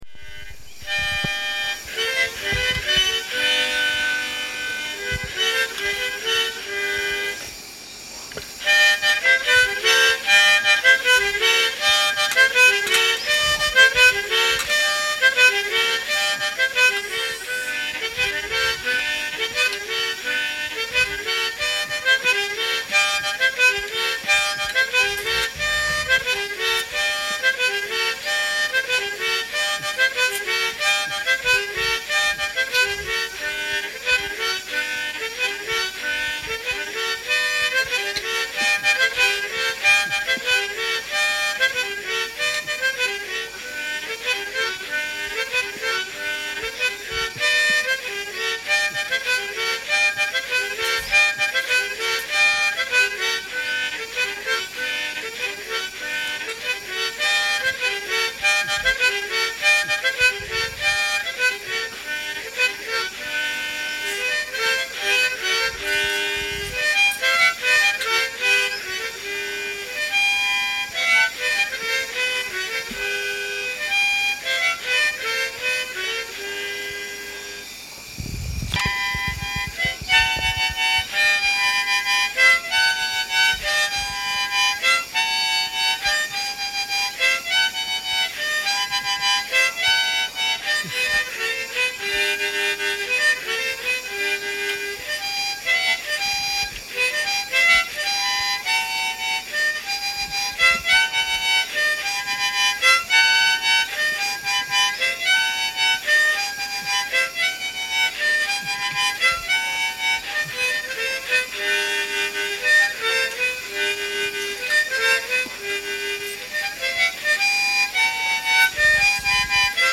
Chocó mouth organ music